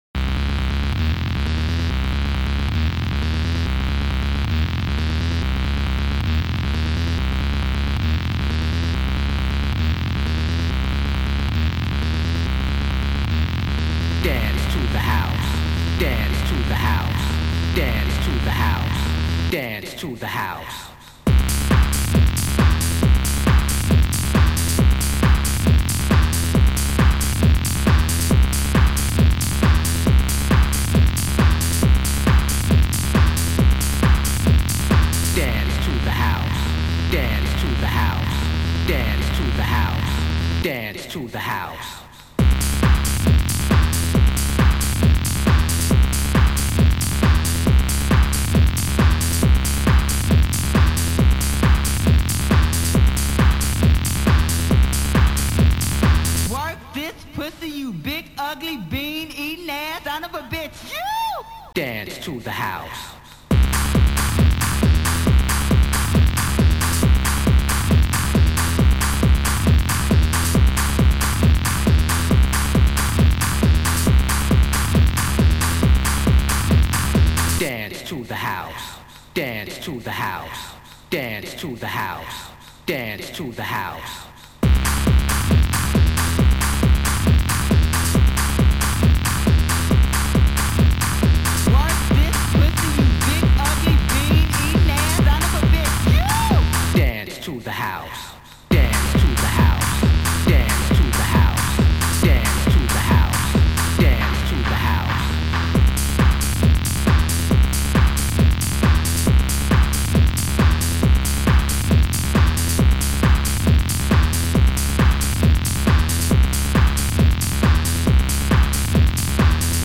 Sound Style: House